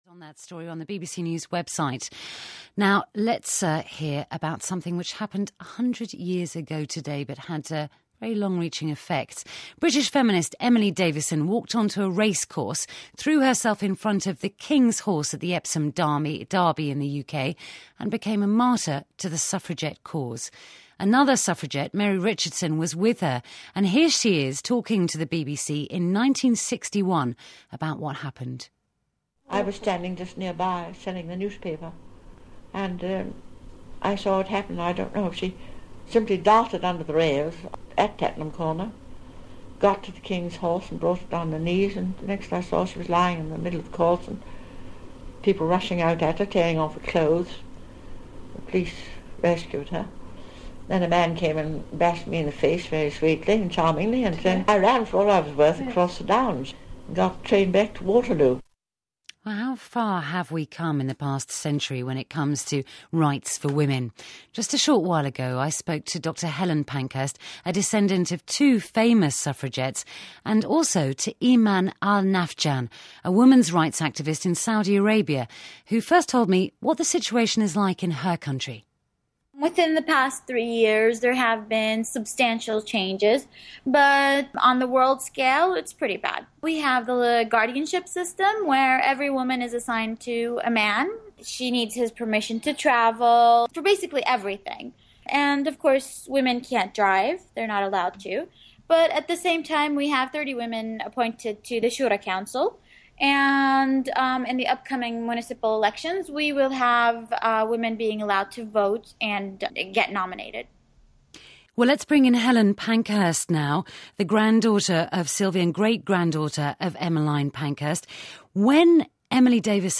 Dr Helen Pankhurst and I were interviewed on BBC radio on women’s rights in Britain and Saudi Arabia.